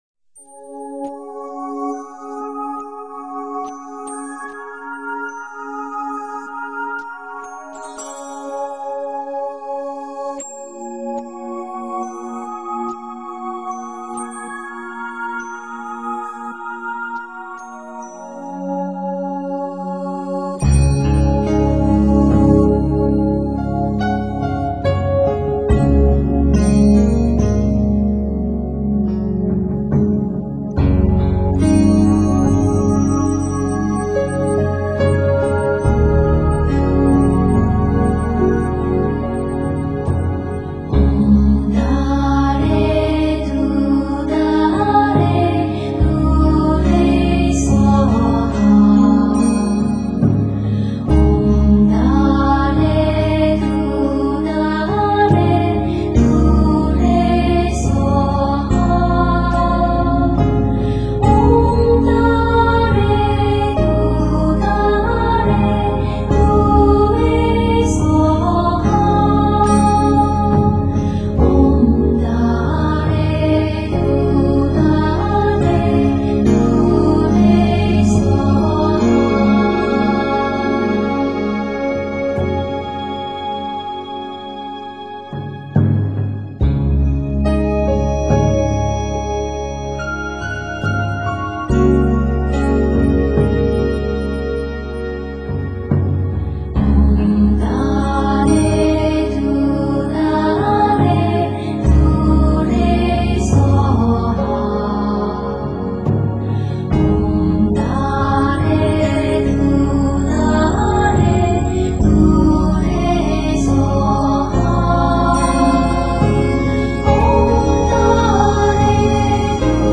平靜、柔和，清澈、空靈
她的歌聲，平靜、柔和、優美，充滿天女般的空靈之美 她的音樂，清新、自在、抒懷，充滿鬆放心神的新世紀之美